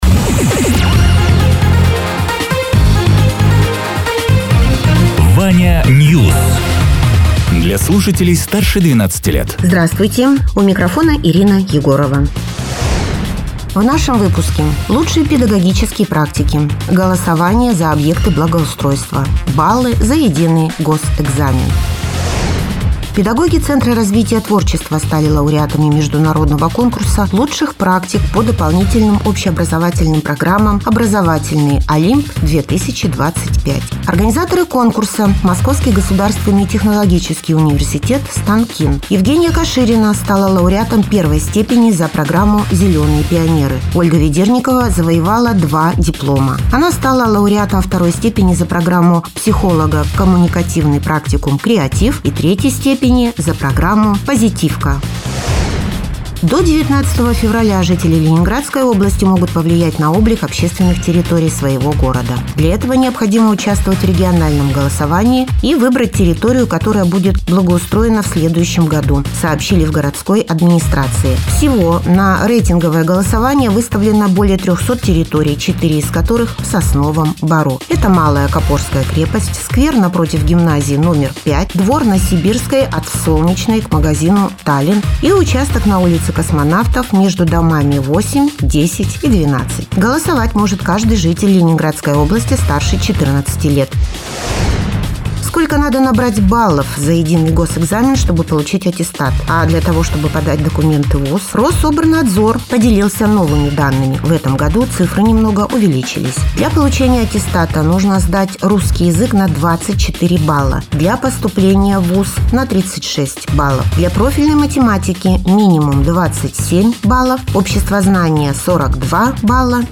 Радио ТЕРА 30.01.2026_12.00_Новости_Соснового_Бора